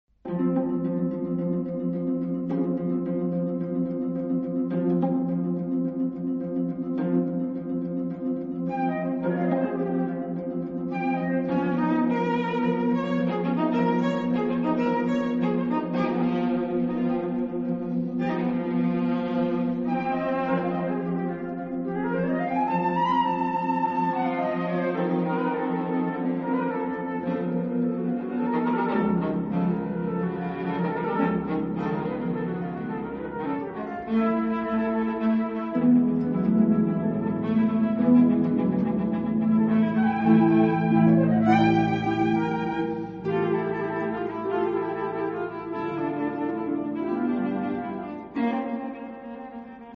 flute
french music
harp
viola